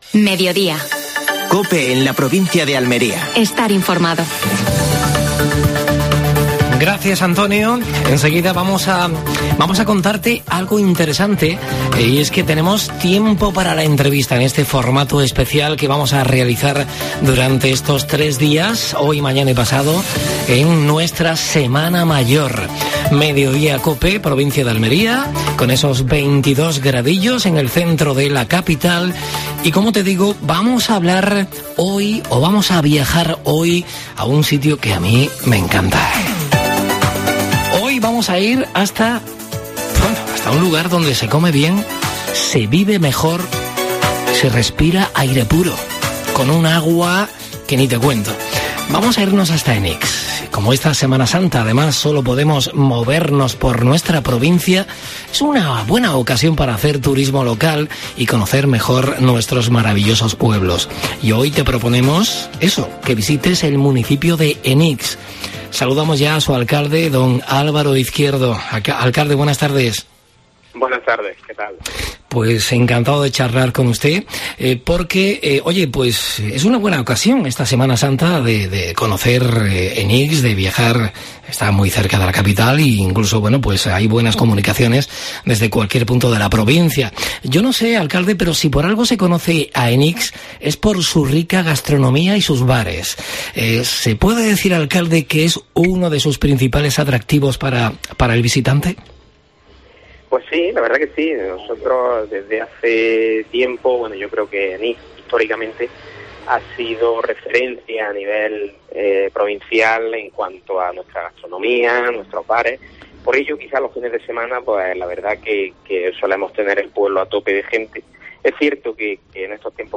AUDIO: Actualidad en Almería. Entrevista a Álvaro Izquierdo (alcalde de Enix).